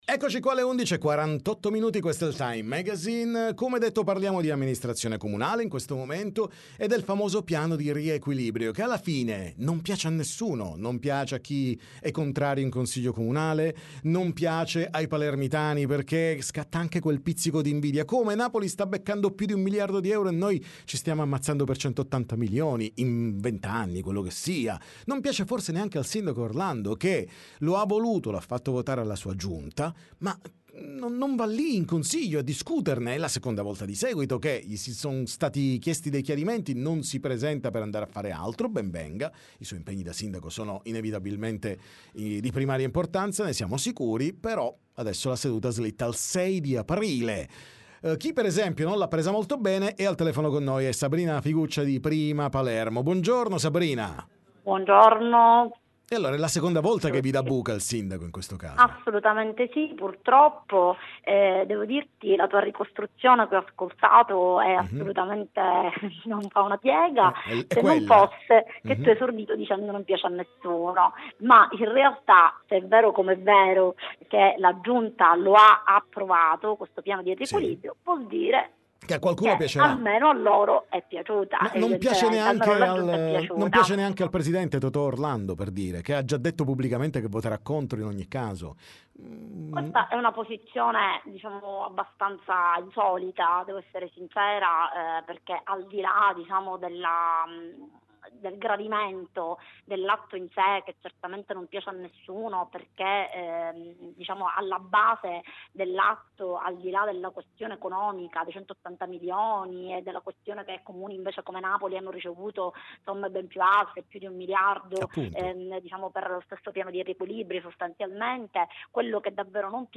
TM Intervista Sabrina Figuccia